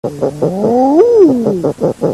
La Macreuse brune